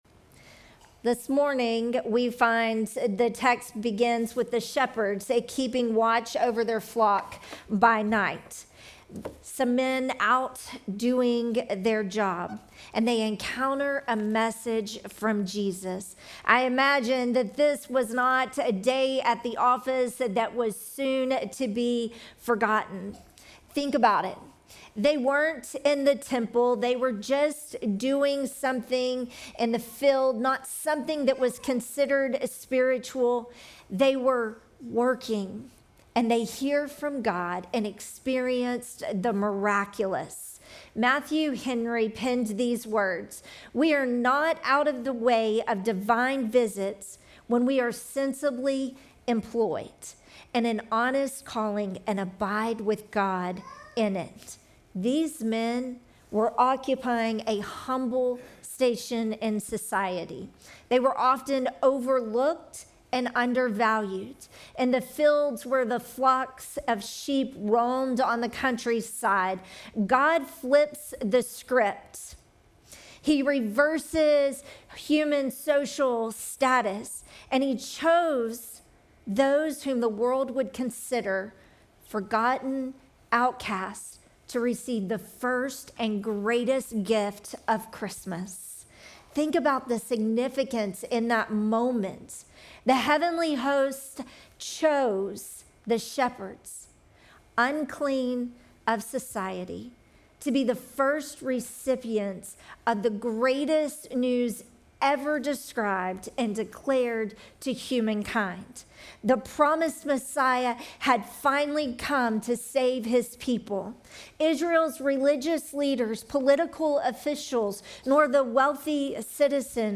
Sermon text: Luke 2:8-20